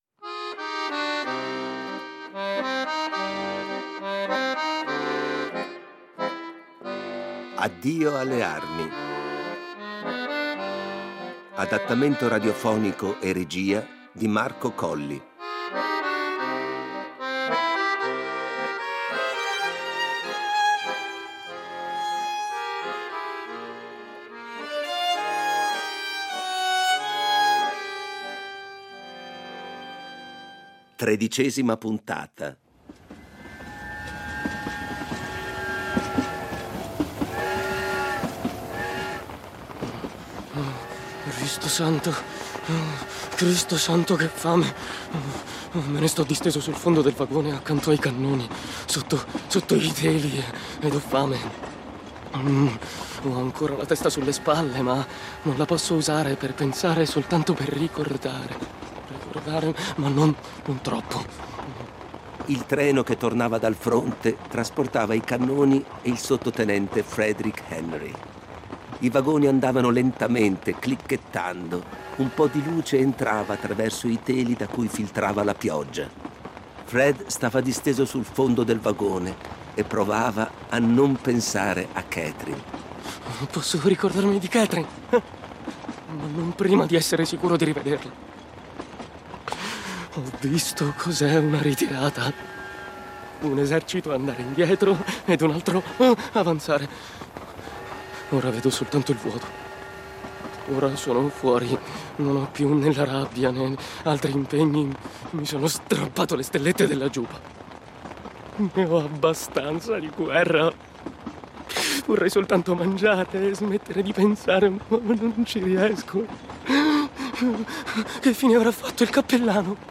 Riduzione, adattamento radiofonico e regia